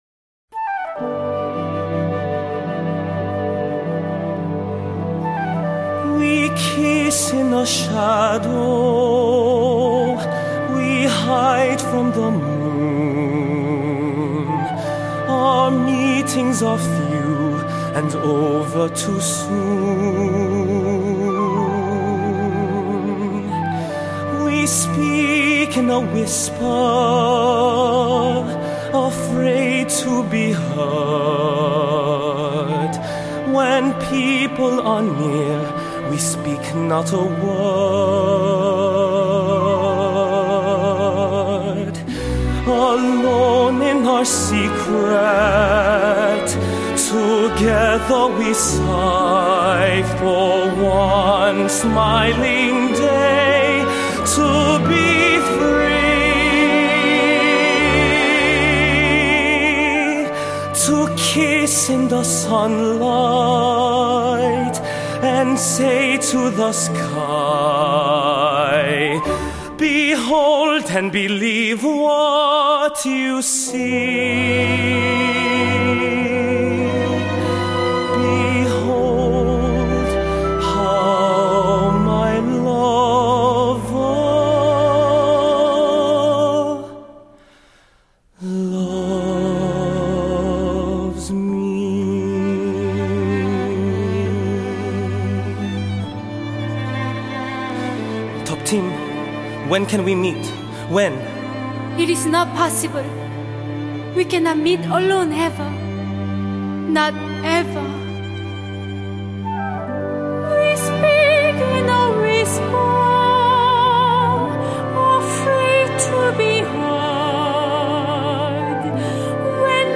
the second most beautiful Broadway song?
Tuptim, Lun-Tha
PDF (below) contains production credits from performances by the St. Paul’s Theatre Guild in Dorchester, MA